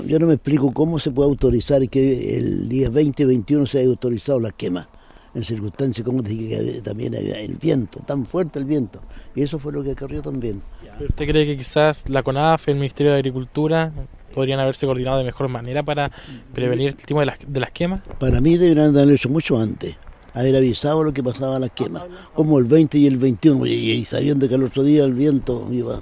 Respecto a esto, el alcalde de la comuna de Traiguén, Luis Álvarez, se refirió a la situación, dejando entrever su molestia, ya que se pudo trabajar de mejor manera la coordinación en cuanto a quemas agrícolas.